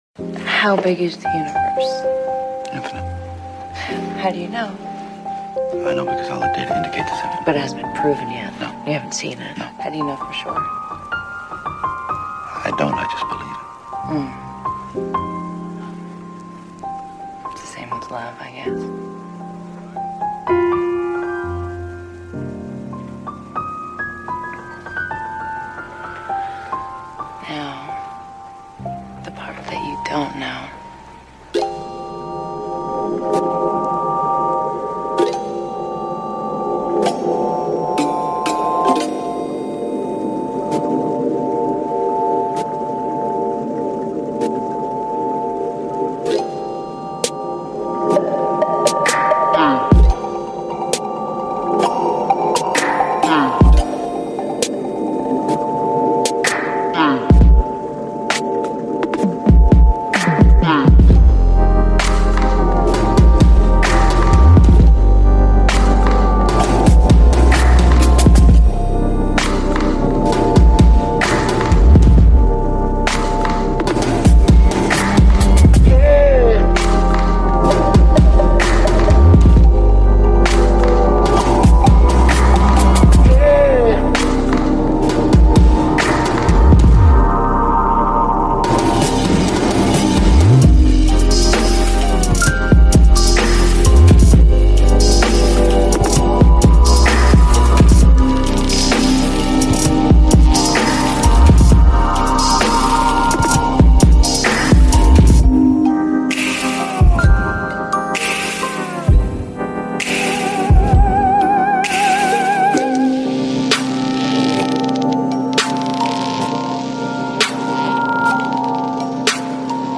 Pluie Jour et Nuit : Étude Longue